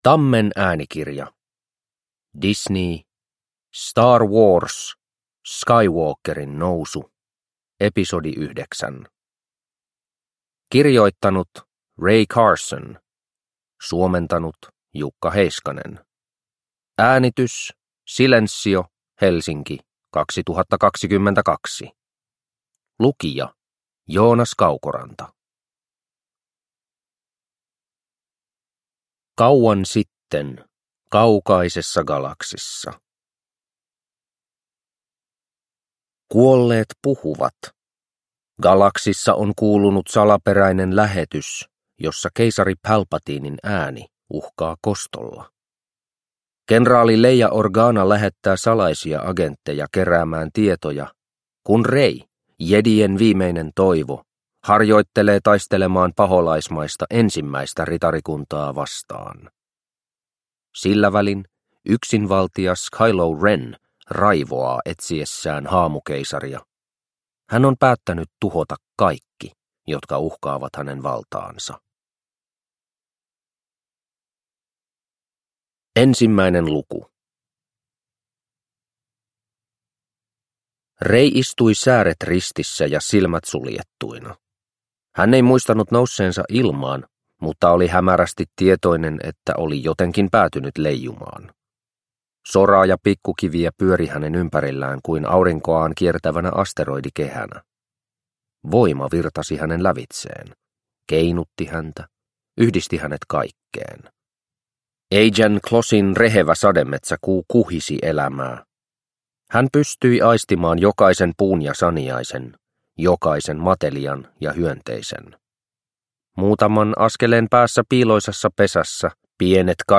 Star Wars. Skywalkerin nousu – Ljudbok – Laddas ner